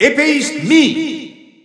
Announcer pronouncing Mii Swordfighter in French.
Mii_Swordfighter_French_EU_Announcer_SSBU.wav